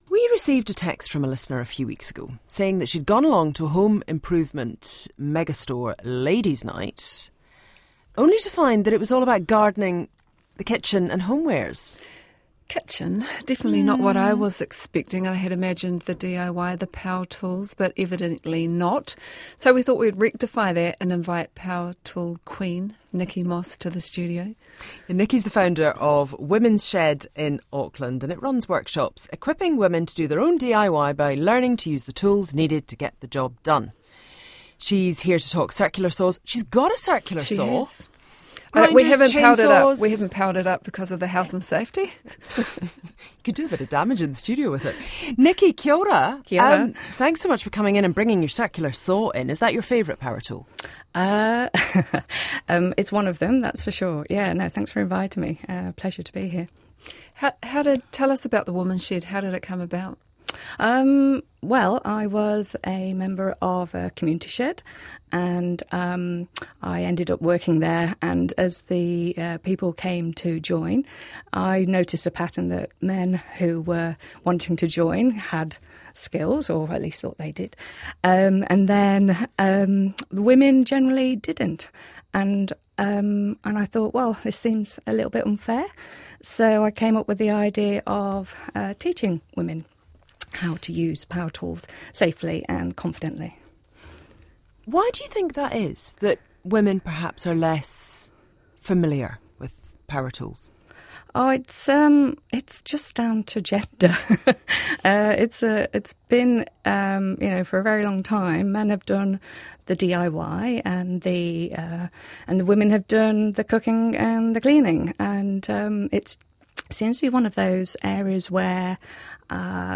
Listen to our RNZ interview